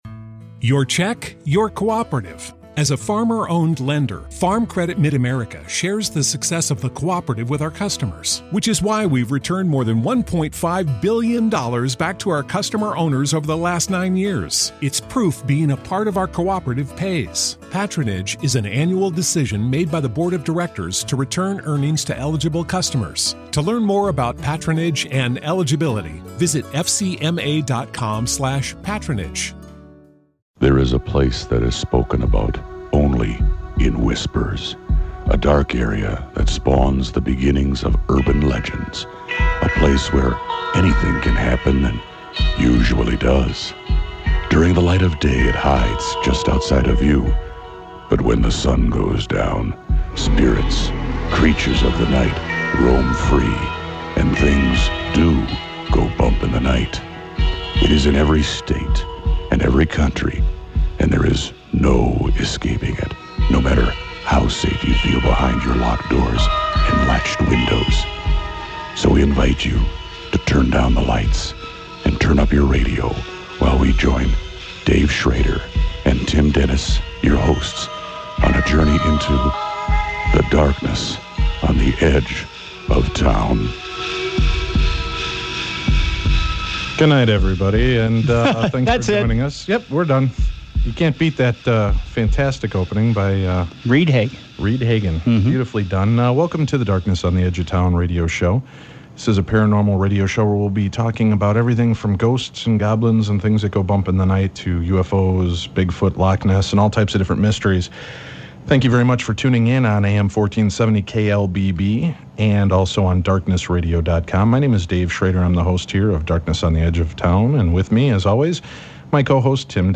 The boys wanted to start the show off with a bang... but they started it off with... salsa music?! But it got better from there, as Jay gave a behind the scenes look at the show and his philosophies on investigating...